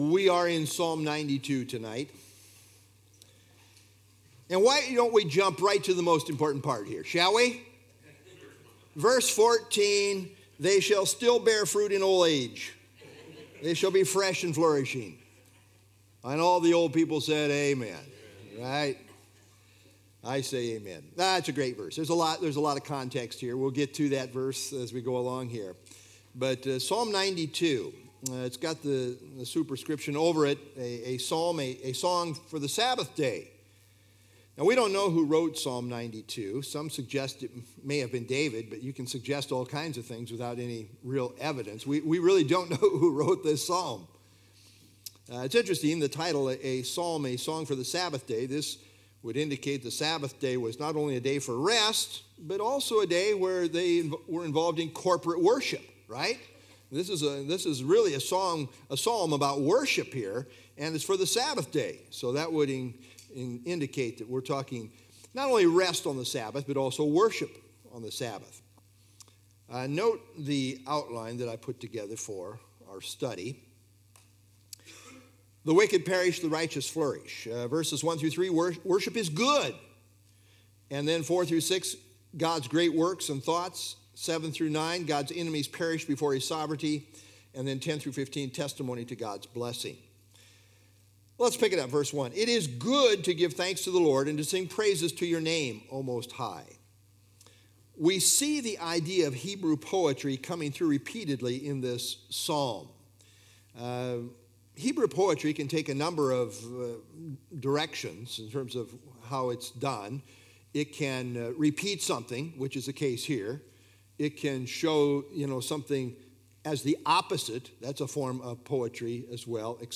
Download FilesPsalm 92 Sermon - Jan 11 2026Psalm 92